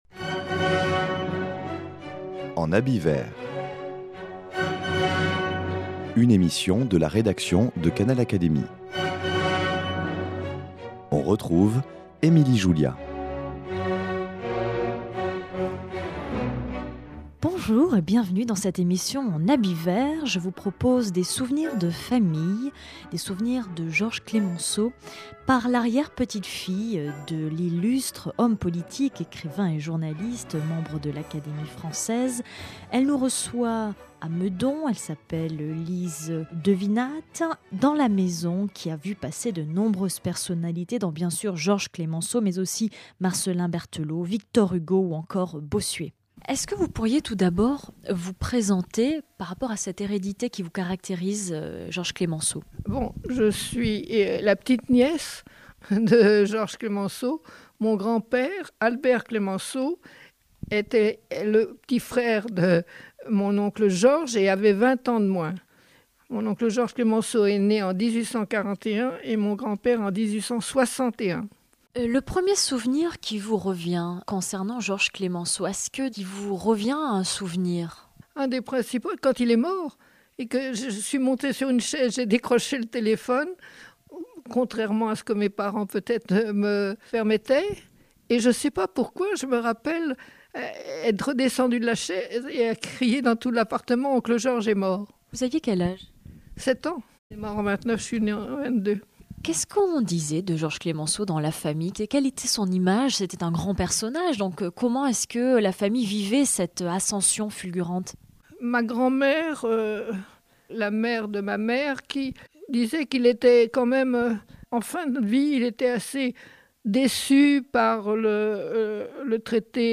Perchée sur les collines de Meudon, c'est une maison calme et chaleureuse qui nous accueille.